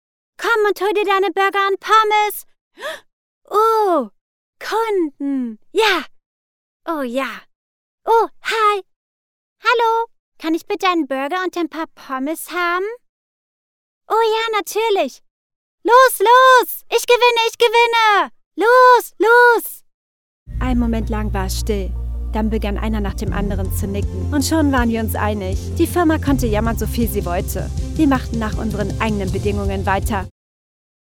Falls genau Du eine junge und frische Stimme suchst, bist Du bei mir genau richtig.
Kein Dialekt
Sprechprobe: Sonstiges (Muttersprache):
Sample-for Cartoon-Kids.MP3